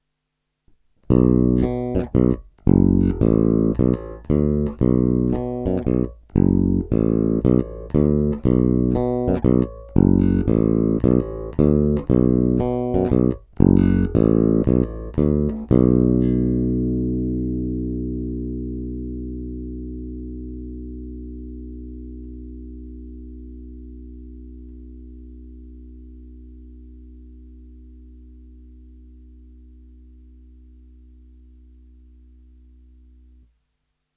Opět nejdříve jen rovnou do zvukovky a ponecháno bez úprav, jen normalizováno.
Kobylkový snímač